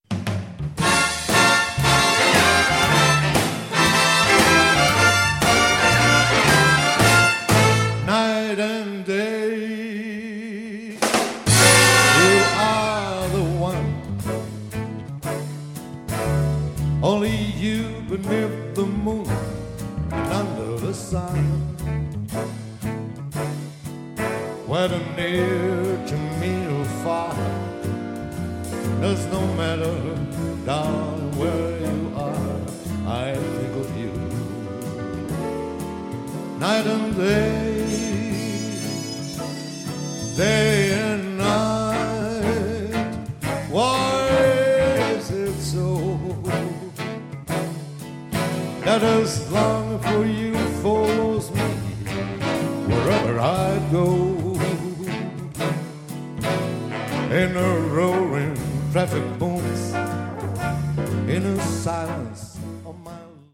Le registrazioni sono tutte dal vivo
ottima la prova di swing dell'orchestra